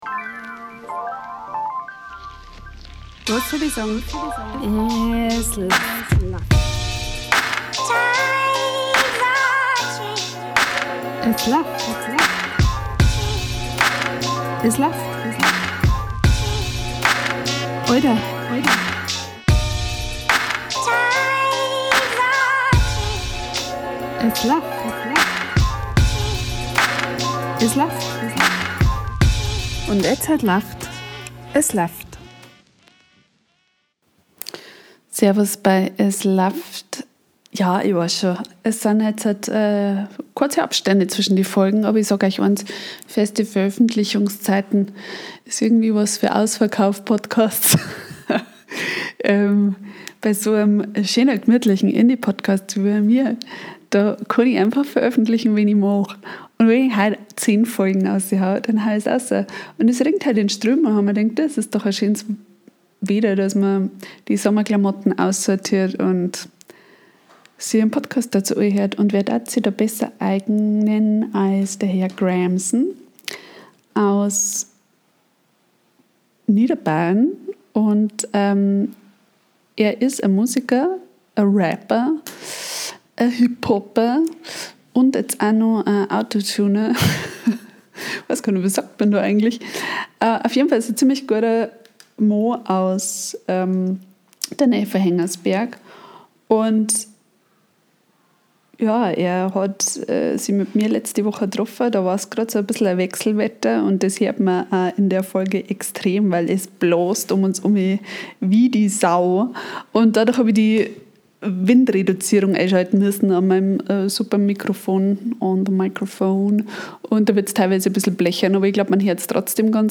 Lass die anderen Podcasts einen Veröffentlichungsplan und Studio-Ton haben. Wir sitzen im Wind an der Donau - und reden übers Leben.